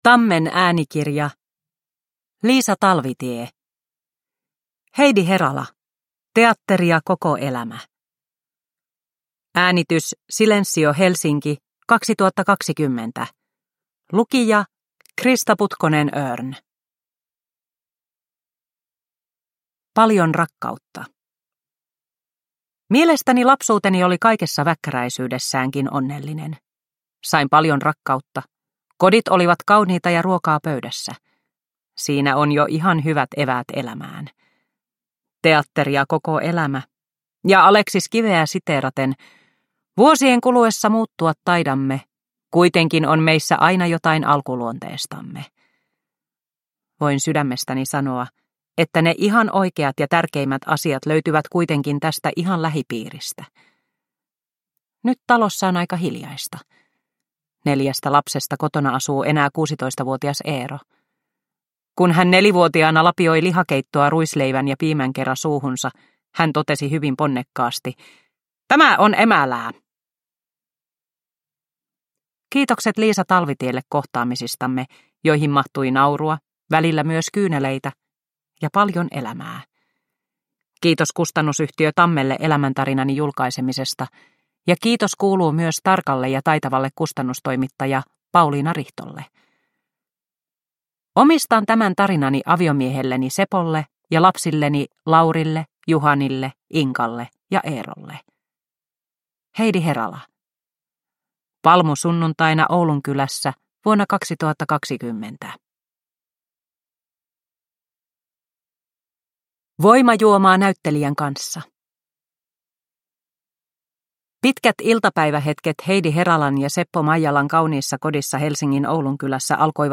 Heidi Herala – Ljudbok – Laddas ner